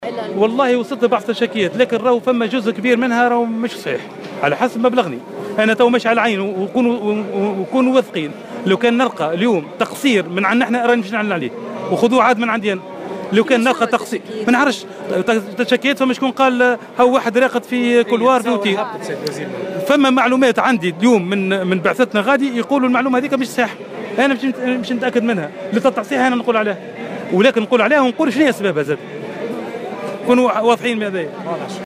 وحول حادثة المعاناة المزعومة لحجيج اضطروا الاقامة بالأروقة نظرا لاكتظاظ أحد الفنادق بالمدينة المنورة، قال وزير الشؤون الدينية إن بعثة الحجيج نفت هذه المعلومة وانه سيتثبت في الأمر كما سيعلن بشكل رسمي عنها في حال ثبوت حصولها، بحسب تأكيده في تصريحات صحفية على هامش استعداد وفد الحجيج الرسمي للمغادرة نحو البقاع المقدسة برئاسة وزير الشؤون الدينية في مطار الحجيج بتونس العاصمة.